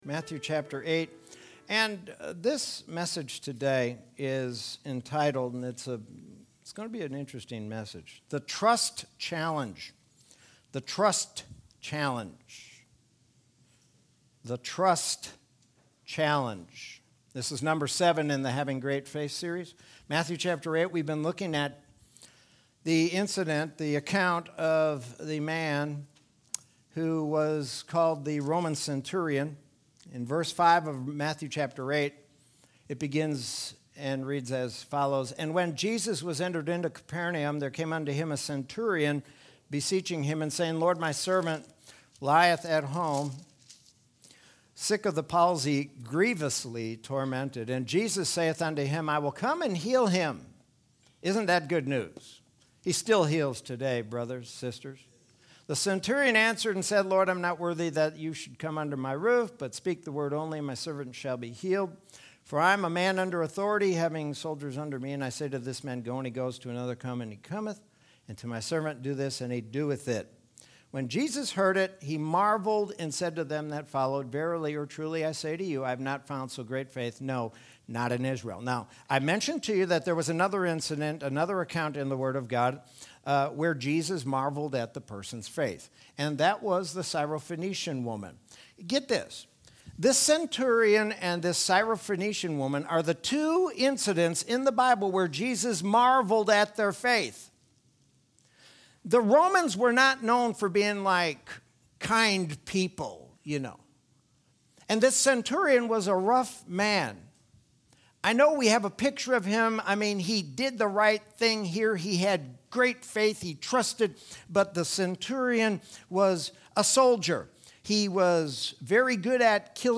Sermon from Sunday, July 25th, 2021.